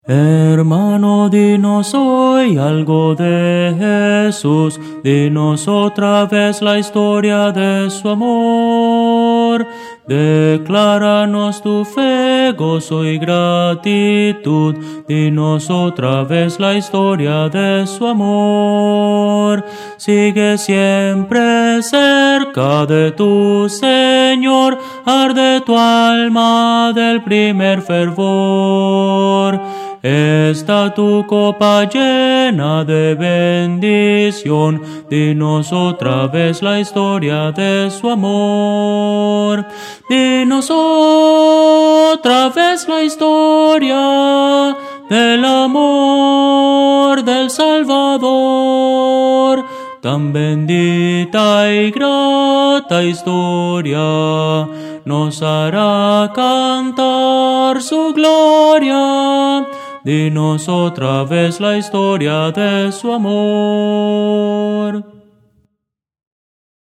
Voces para coro
Soprano